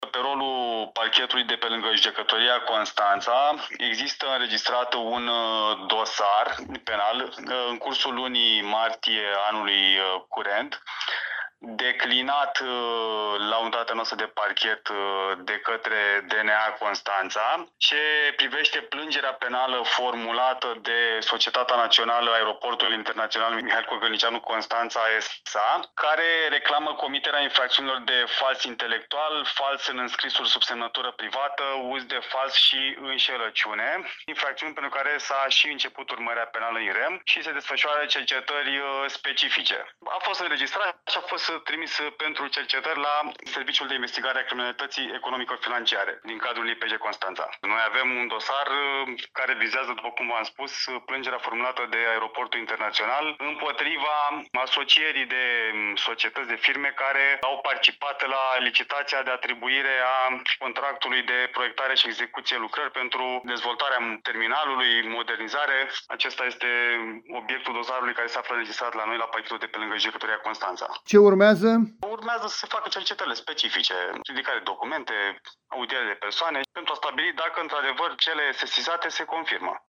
Punct de vedere al prim-procurorului Mihai Stanciu, de la Parchetul de pe lângă Judecătoria Constanța